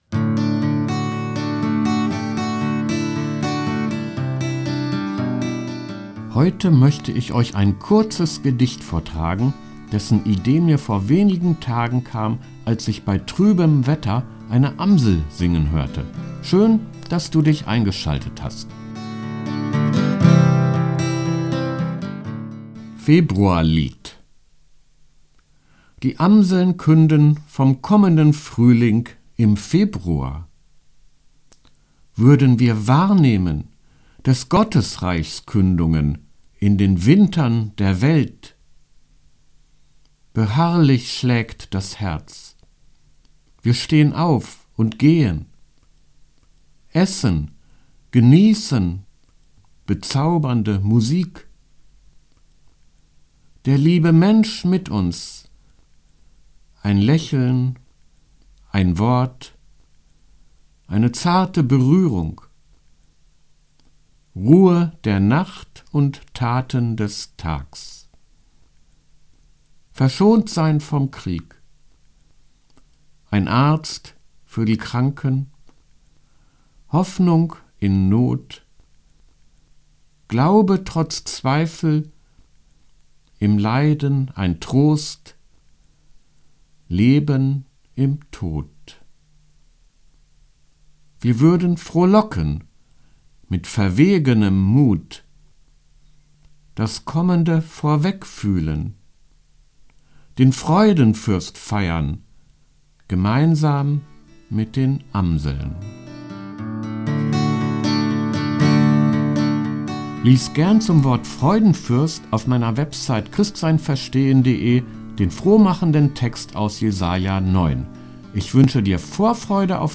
Ein Gedicht